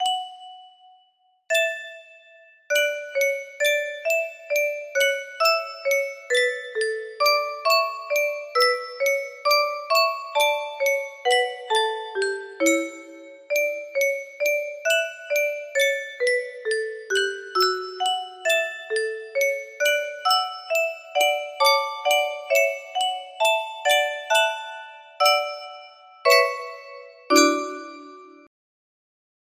Solemn music box melody